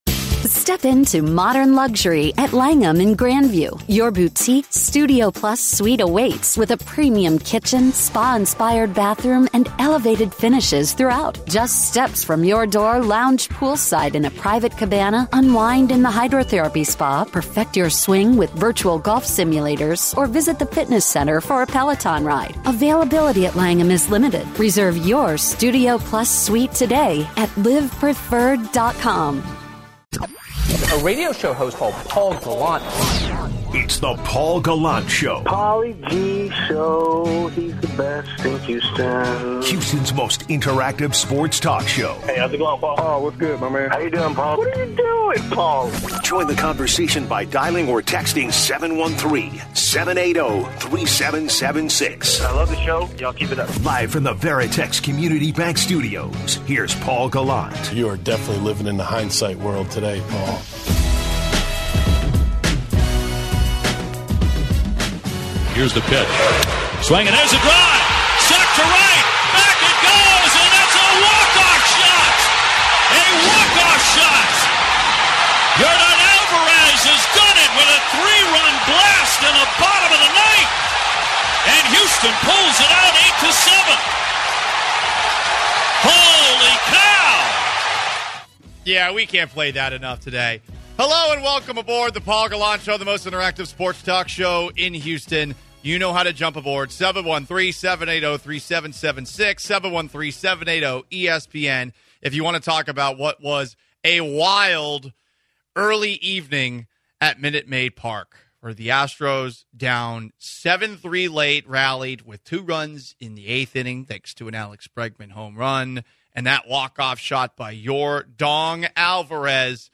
Then former MLB manager Joe Maddon joins the show to give his perspective on the thriller to kick off the MLB Postseason.